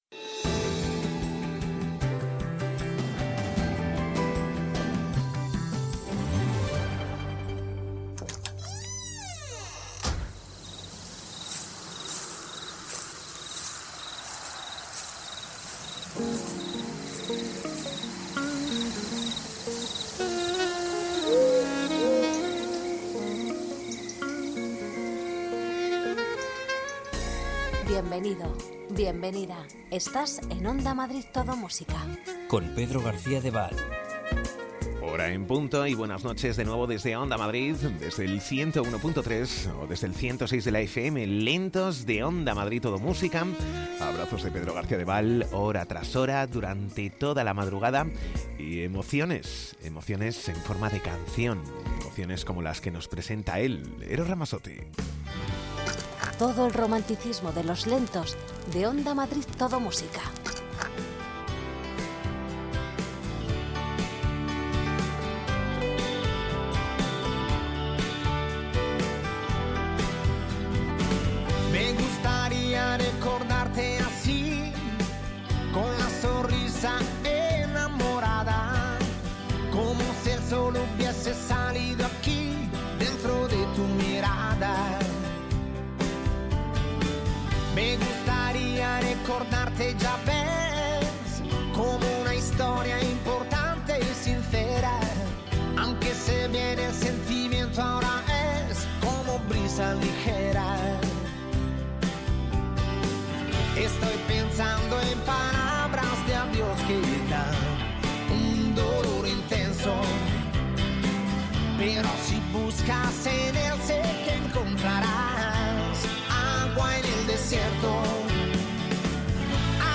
Los mejores lentos
Ritmo tranquilo, sosegado, sin prisas...